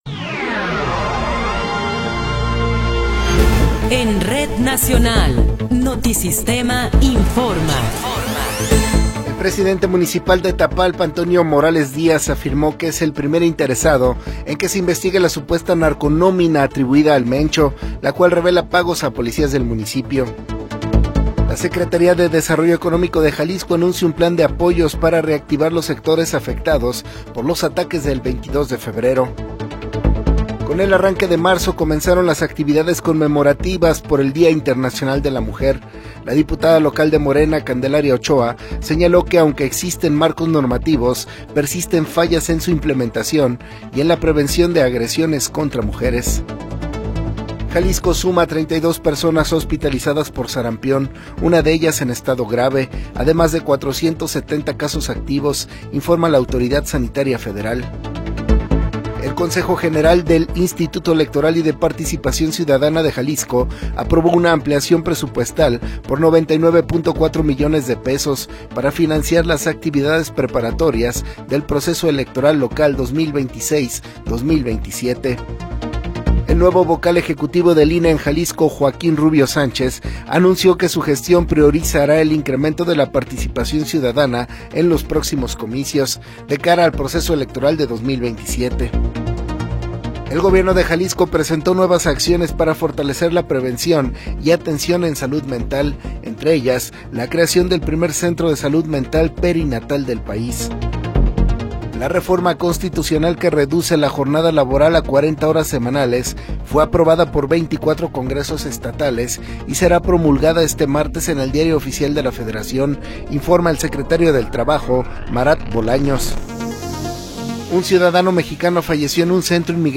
Noticiero 21 hrs. – 2 de Marzo de 2026